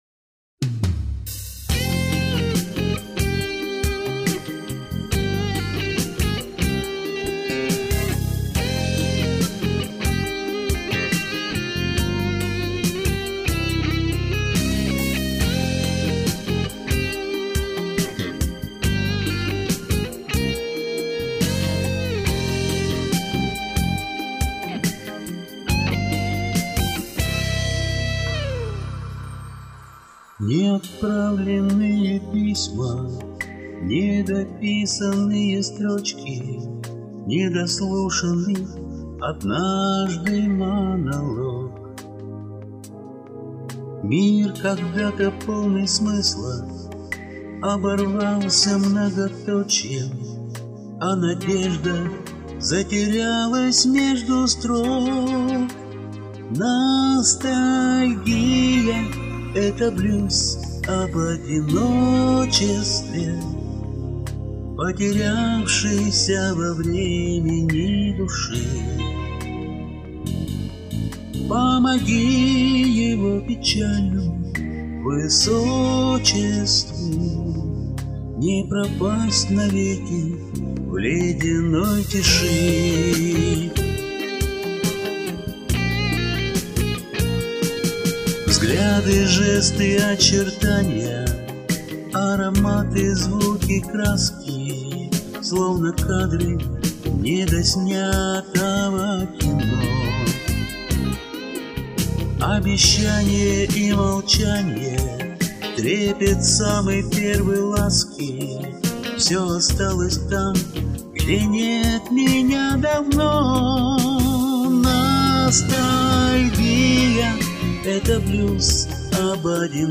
характерный "металлический" призвук в записи голоса ....